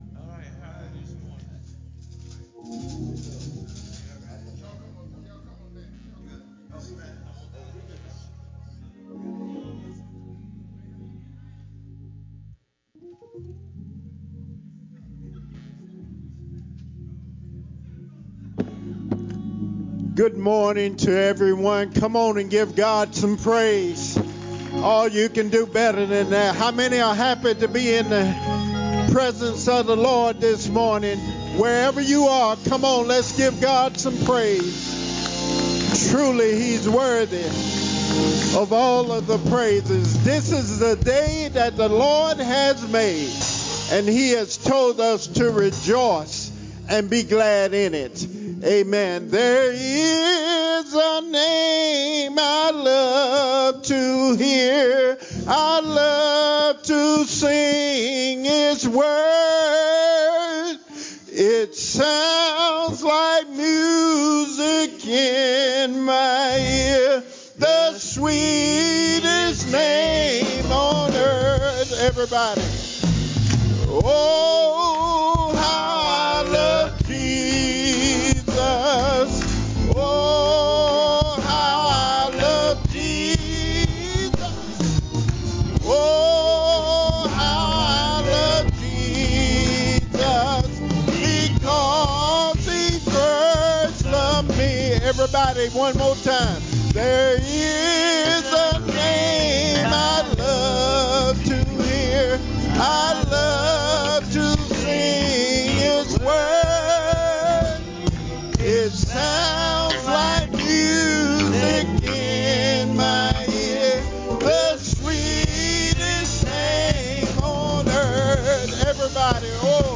7:30 A.M. Service: Hosanna!!!